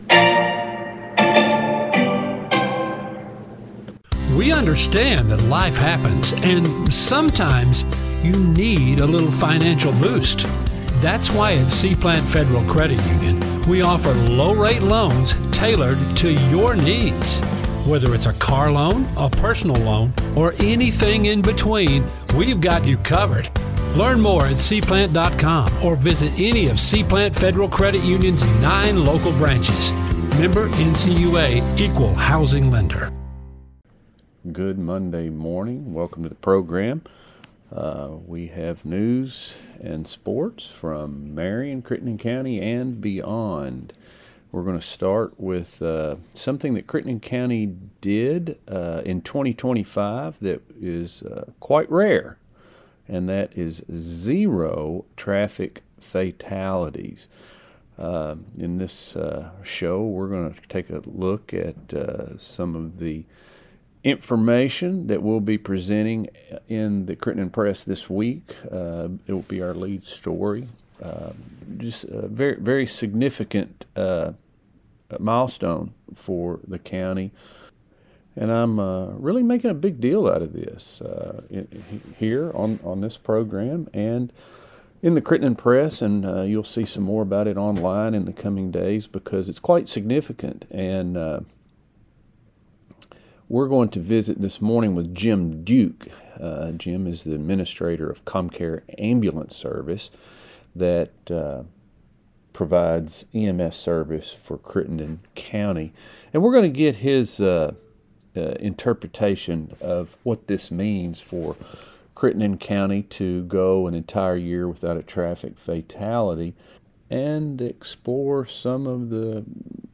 News | Sports | Interviews